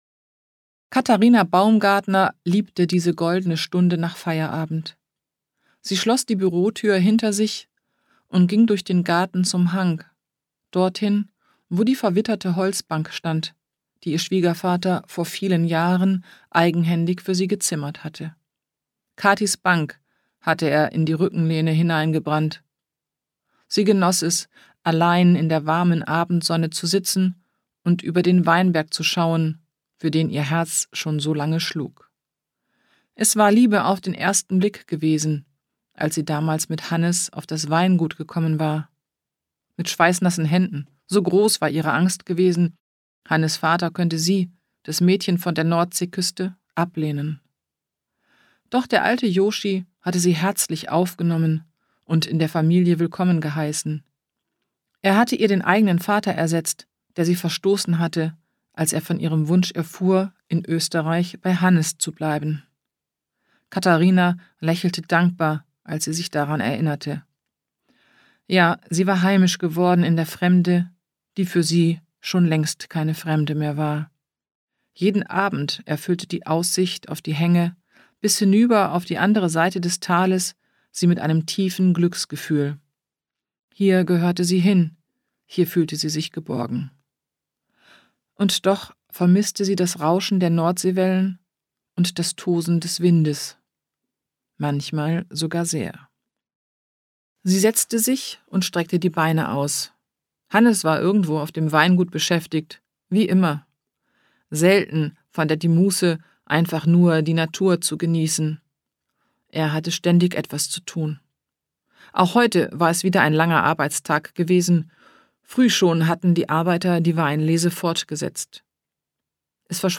Die von Fans ausgewählten Publikumslieblinge seiner Ostfriesenkrimis spricht Klaus-Peter Wolf auf dieser einzigartigenAusgabe und macht damit seinen Hörerinnen und Hörern ein ganz besonderes Geschenk.